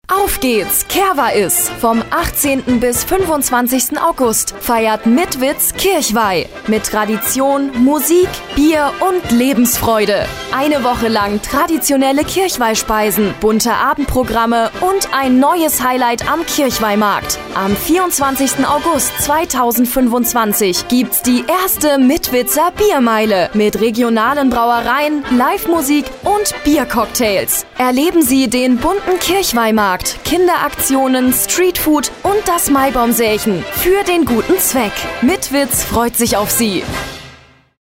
Radio Eins präsentiert: Unser neuer Kirchweih-Spot ist da!